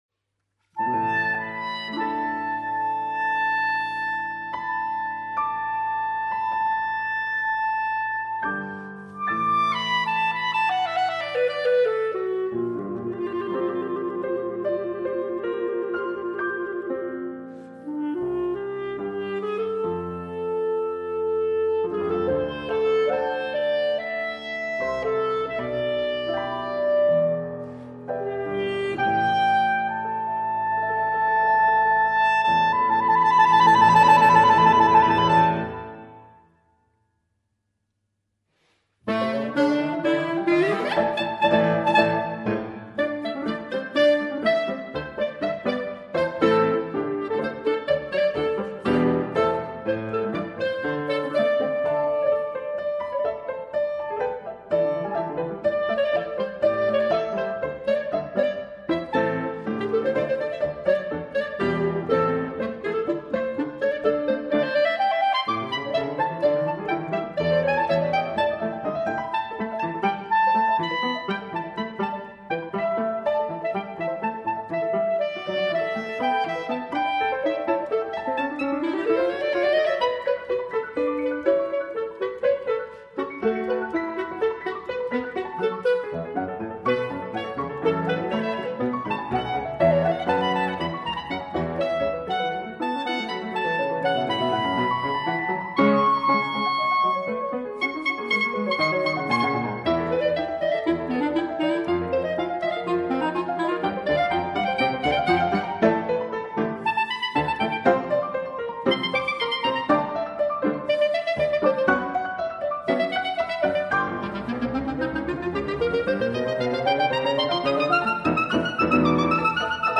CLARINETTO , E PIANOFORTE